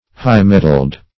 Search Result for " high-mettled" : The Collaborative International Dictionary of English v.0.48: High-mettled \High"-met`tled\, a. Having abundance of mettle; ardent; full of fire; as, a high-mettled steed.
high-mettled.mp3